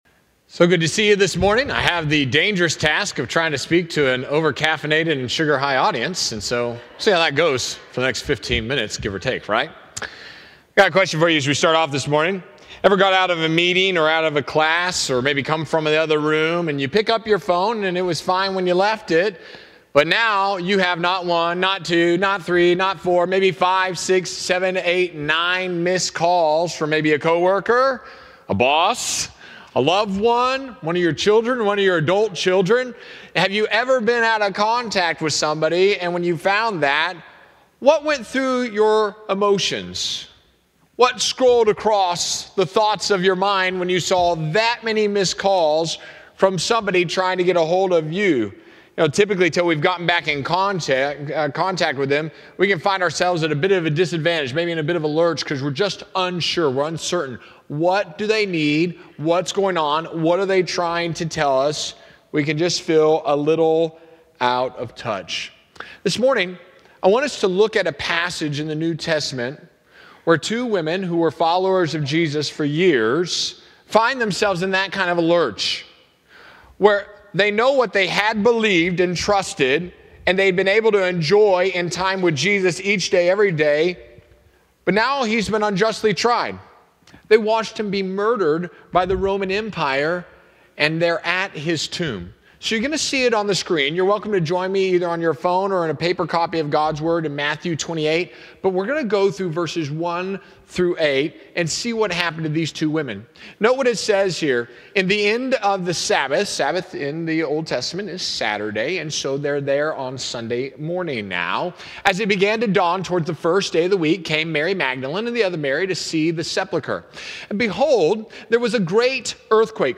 Sermons | Faith Baptist Church
Easter Sunday 4/20/25